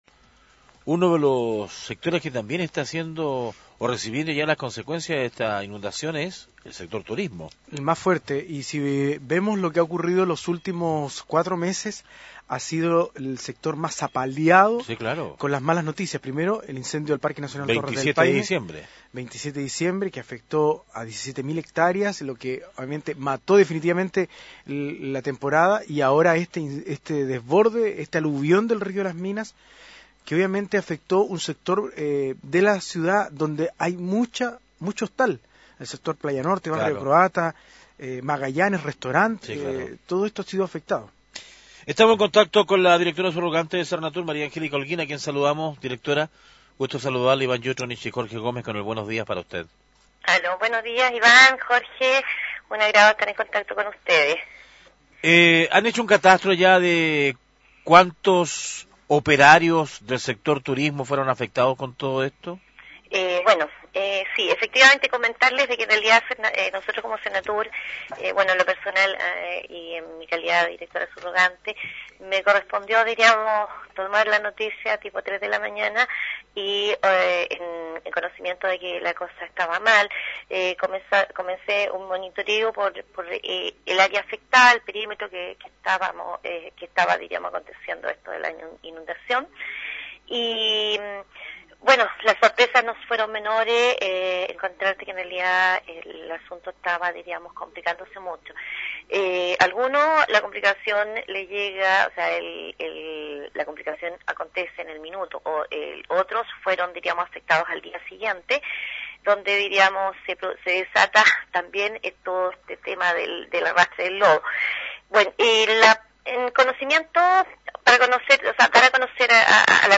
Entrevistas de Pingüino Radio - Diario El Pingüino - Punta Arenas, Chile